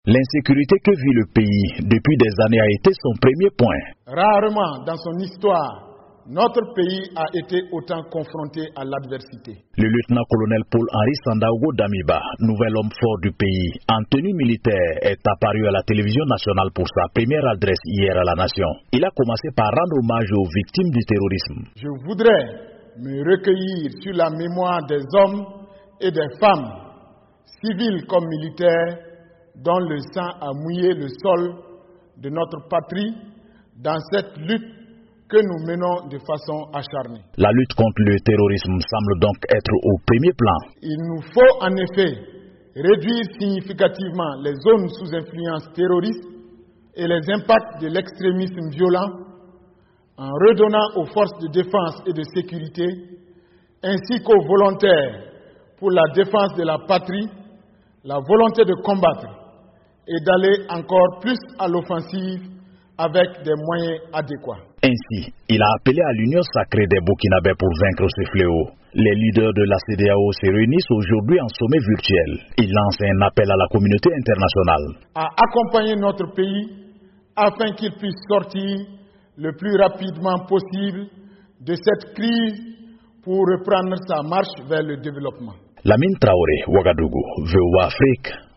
Première adresse du lieutenant-colonel Paul-Henri Sandaogo Damiba
Jeudi soir, dans sa première allocution depuis sa prise de pouvoir lundi, le nouvel homme fort du Burkina, le lieutenant-colonel Paul-Henri Sandaogo Damiba, avait déclaré à la télévision nationale que son pays avait "plus que jamais besoin de ses partenaires".